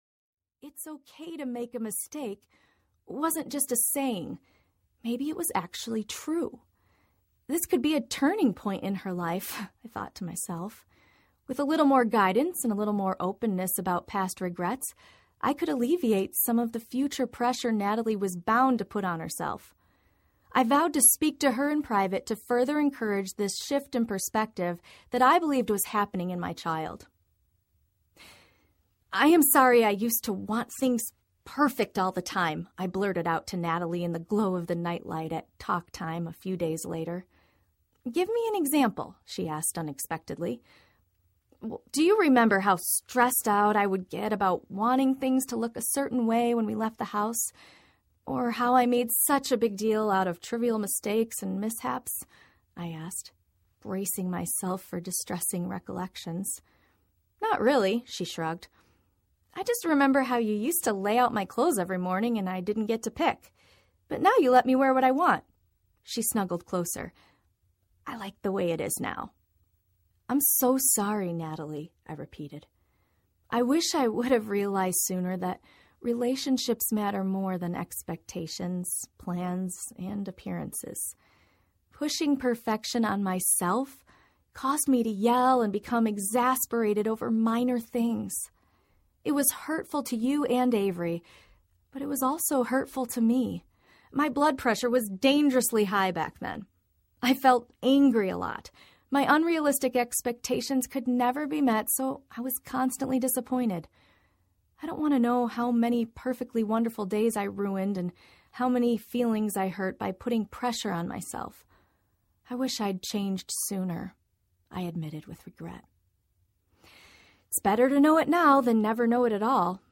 Hands Free Life Audiobook
6.97 Hrs. – Unabridged